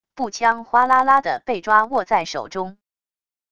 步枪哗啦啦的被抓握在手中wav音频